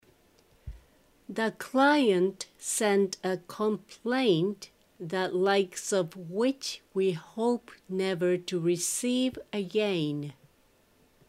ゆっくり：